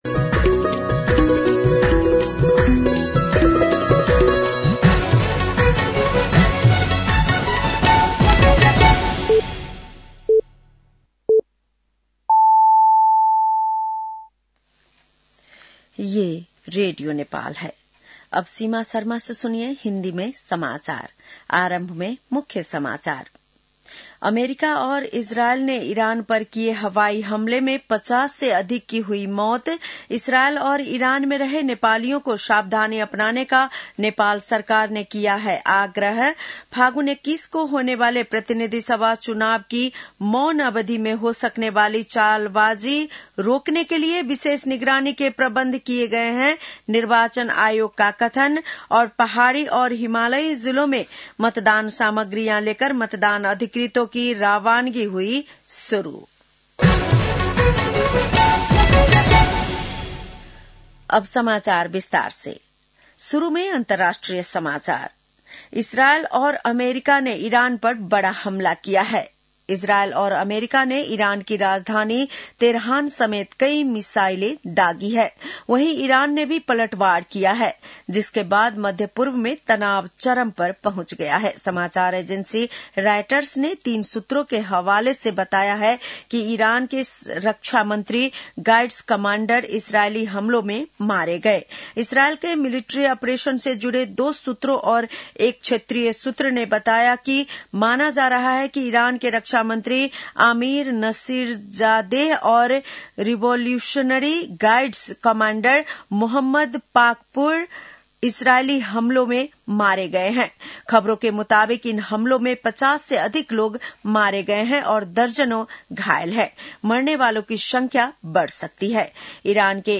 बेलुकी १० बजेको हिन्दी समाचार : १६ फागुन , २०८२